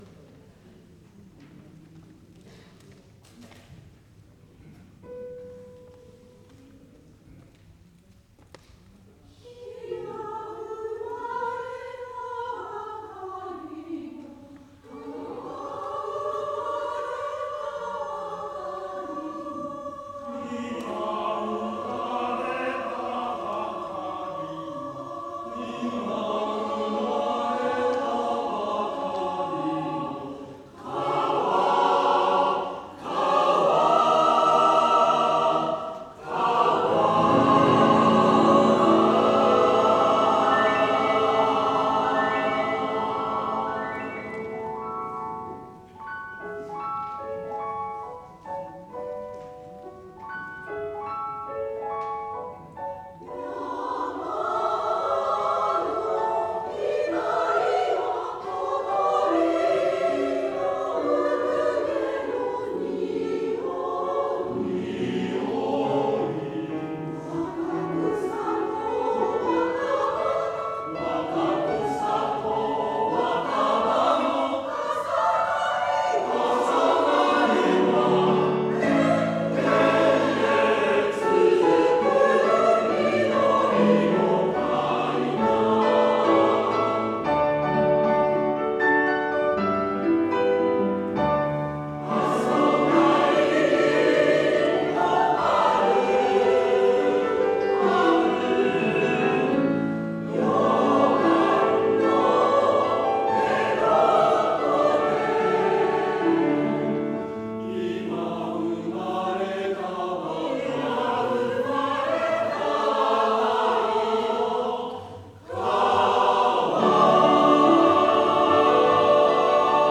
令和６年１０月２７日第２７回定期演奏会を開催しました。
第１ステージ 「ホームソングメドレーⅠⅡ」源田俊一郎 編曲 音声 写真第２ステージ ヴァイオウン演奏 愛の三部作 音声 第３ステージ 混声合唱組曲「筑後川」團伊玖磨 作曲 音声 写真 アンコール 大地讃頌、ふるさと 音声
令和６年１０月２７日（日）黒崎ひびしんホールの大ギールで 第２７回定期演奏会を 開催しました。